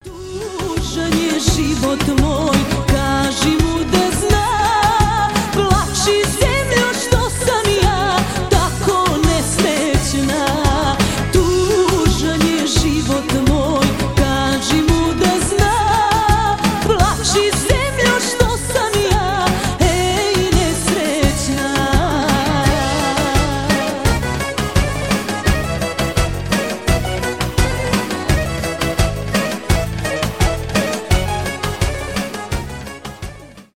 фолк
мелодичные